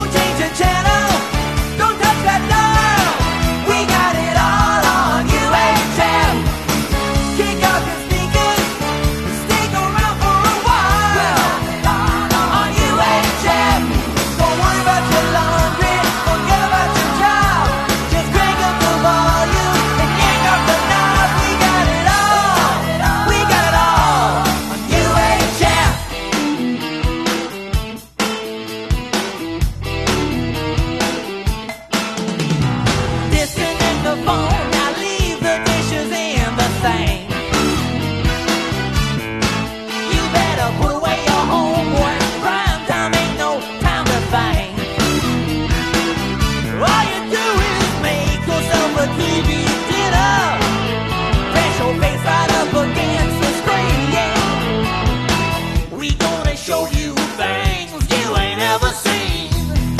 live in concert from start to finish